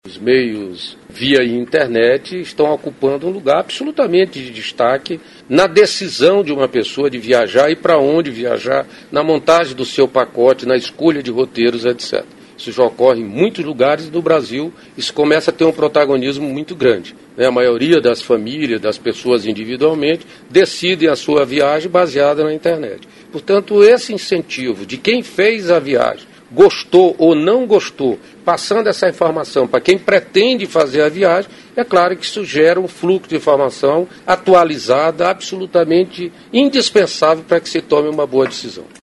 aqui e ouça declaração do ministro Gastão Vieira sobre a importância do compartilhamento de experiências de viagens.